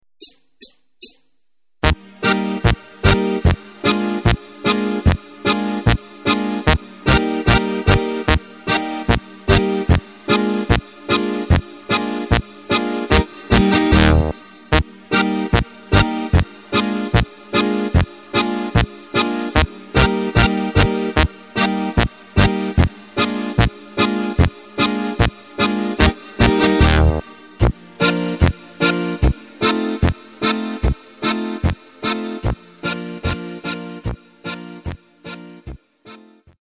Takt:          2/4
Tempo:         149.00
Tonart:            Eb
Playback Demo